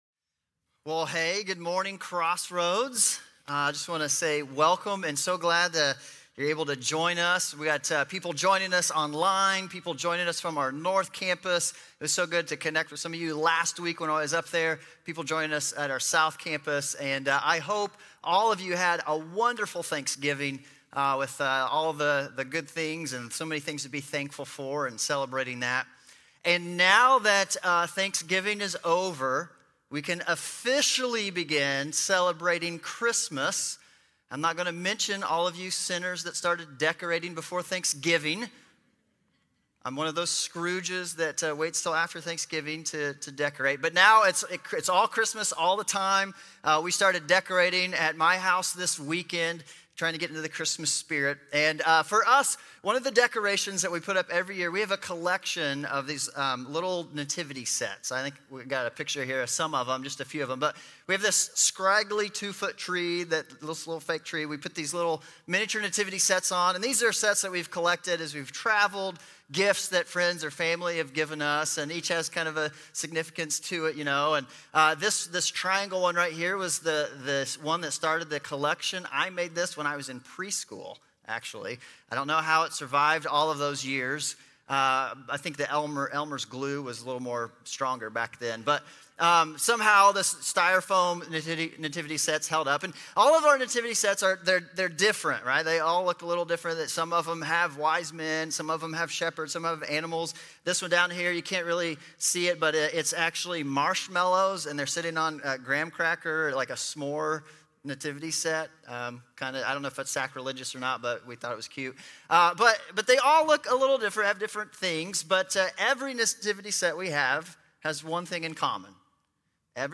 Audio Sermons